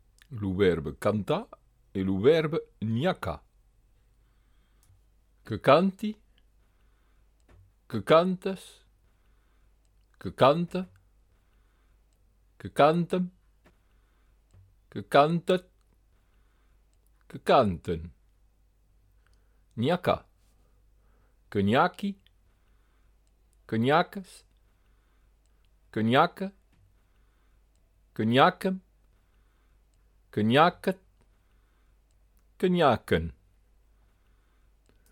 Présent de l’indicatif en gascon noir : en gras l’endroit de l’accentuation vocale
Vous noterez qu’en negue on accentue toujours sur le devant du verbe au présent de l'indicatif.